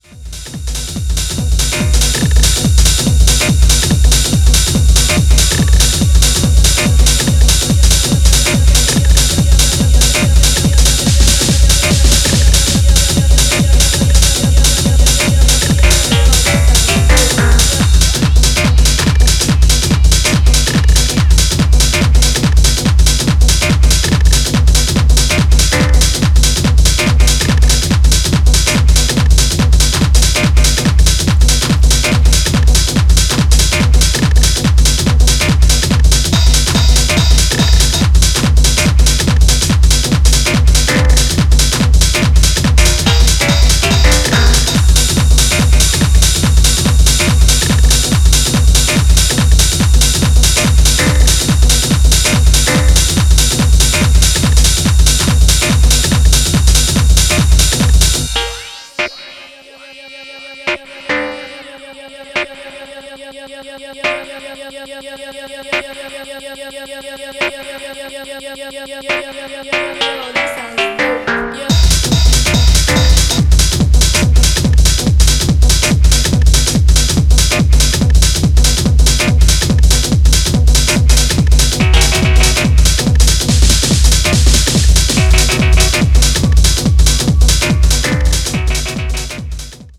Styl: Techno Vyd�no